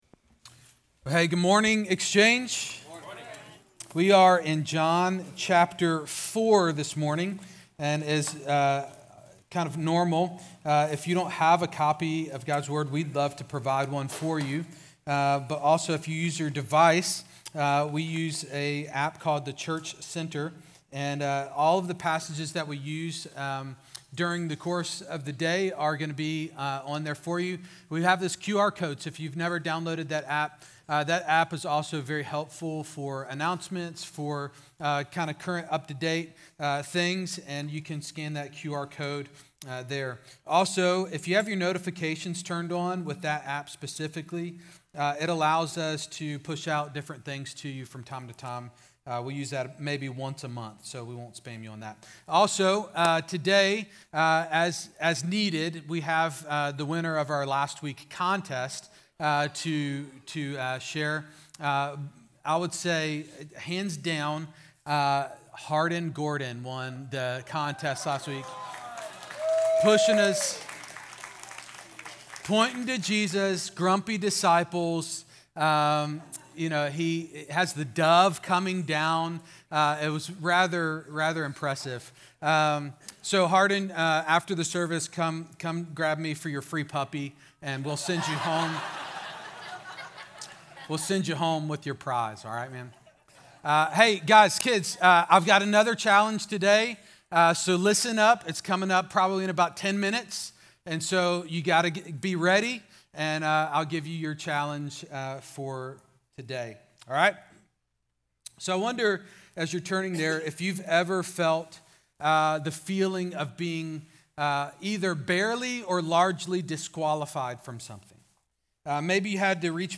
Exchange Church Sermons